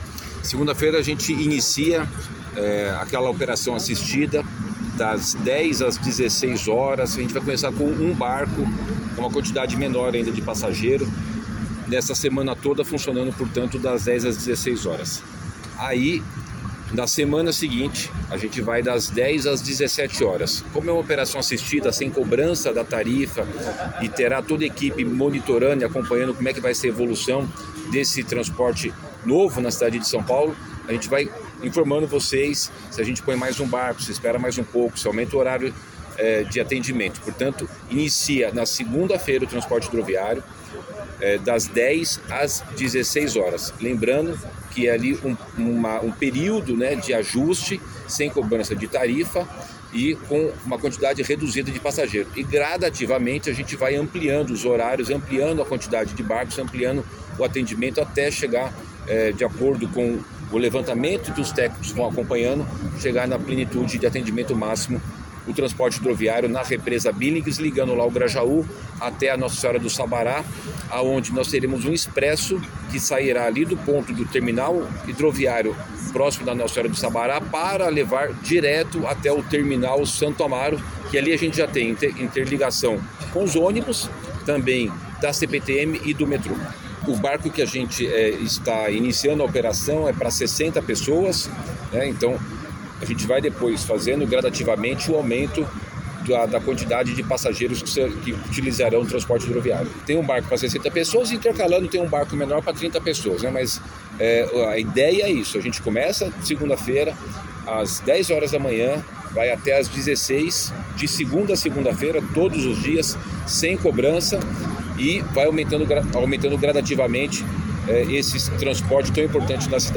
Aquático de SP começa a funcionar na segunda (13) das 10h às 16h sem cobrança de tarifa, diz Nunes – OUÇA A ENTREVISTA